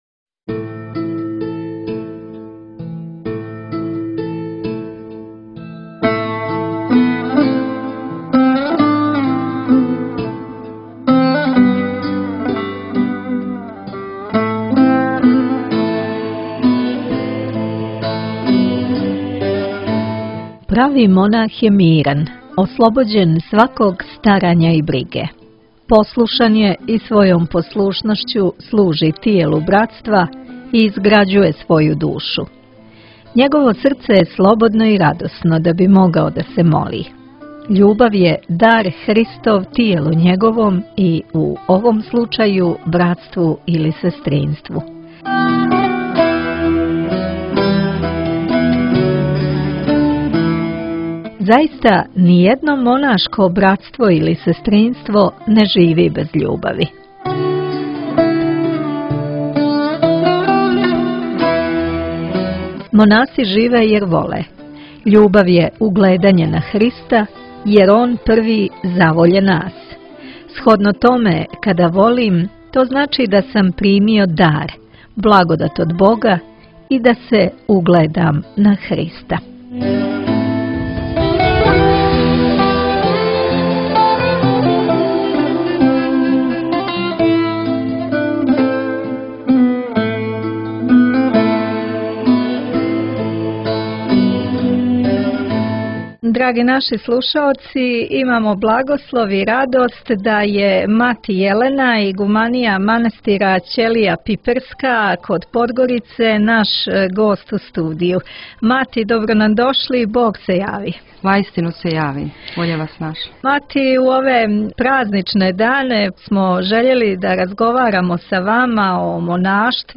Митрополит Амфилохије служио у манастиру Ћелија пиперска